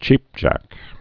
(chēpjăk)